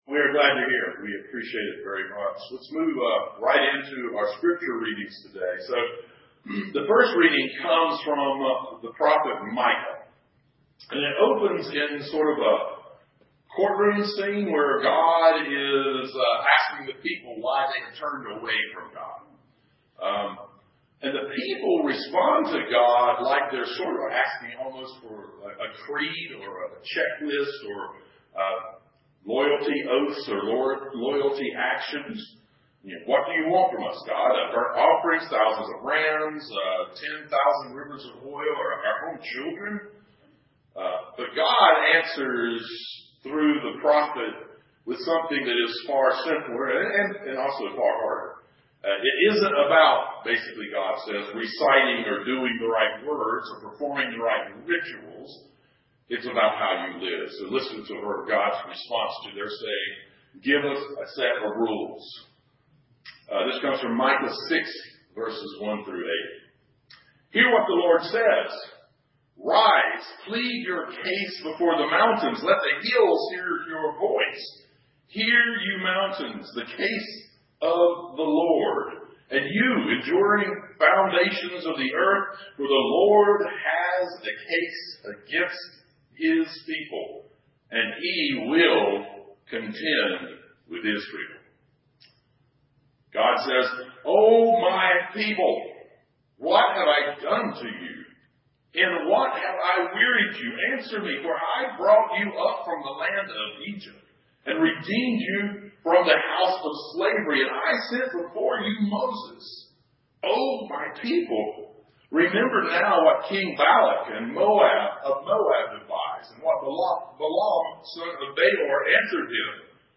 Sermon Series: What if? Faith Beyond the Training Wheels.